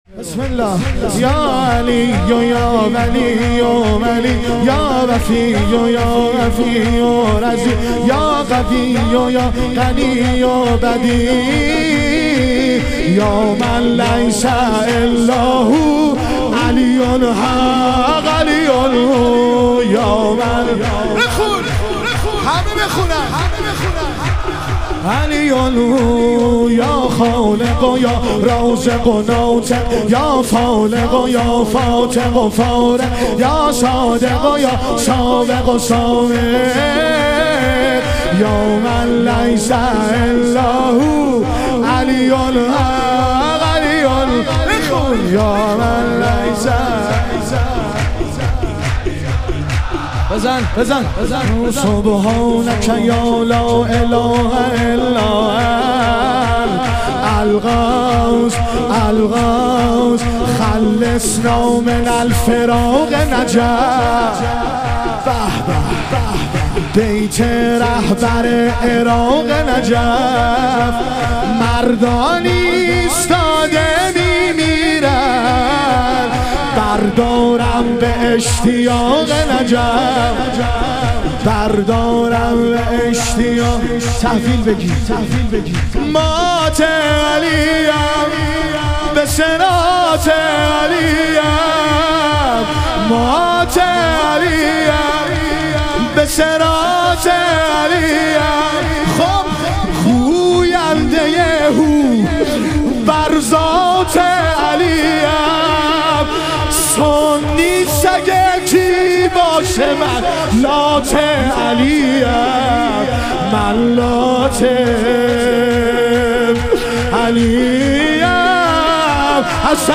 ظهور وجود مقدس رسول اکرم و امام صادق علیهم السلام - شور